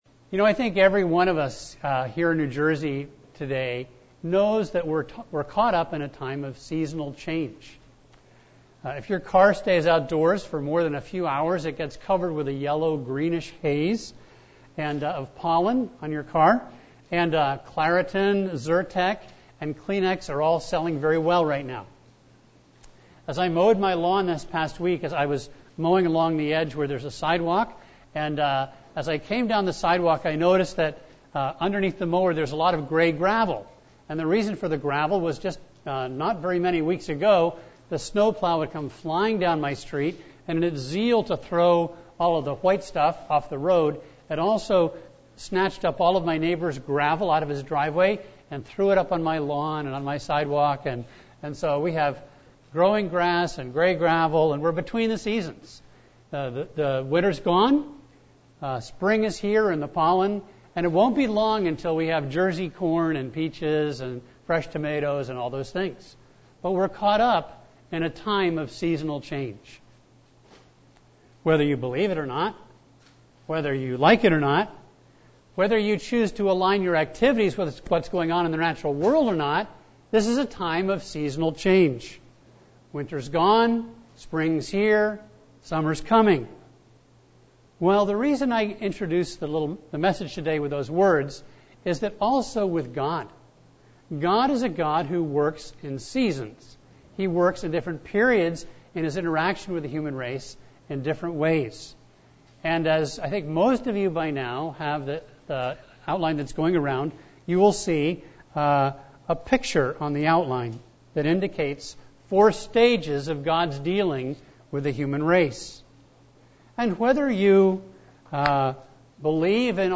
A message from the series "40 Days of Love."